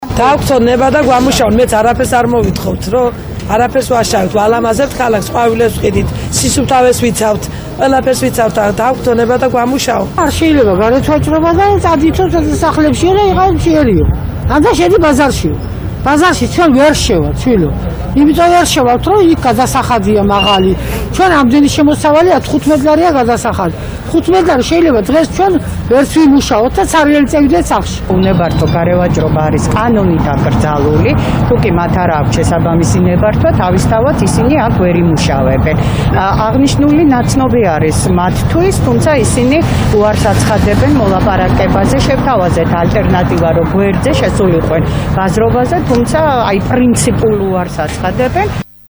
გარემოვაჭრეების ხმა